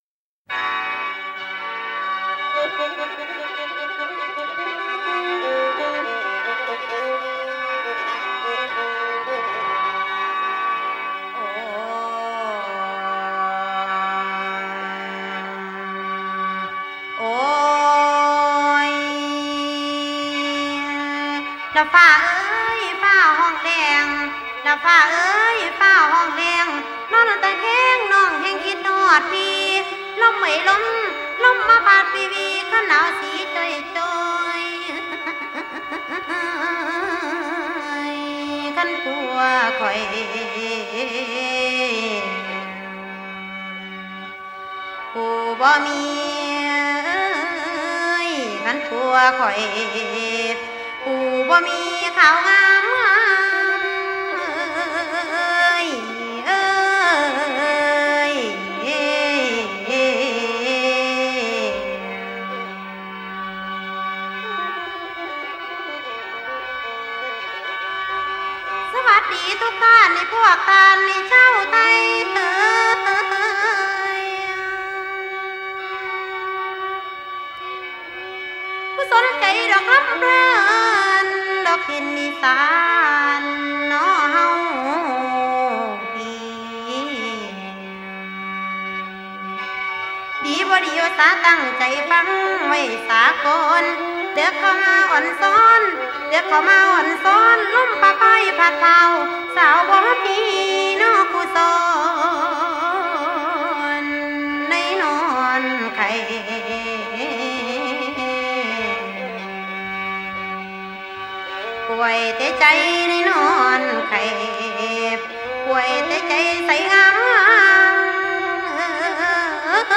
1970s ディープ＆ファンキー田園民謡インスト from イサーン!!!!!!!
（※モーラムはタイ東北部（通称イサーン）特有の音楽様式。）